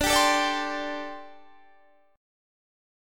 Listen to D7sus4 strummed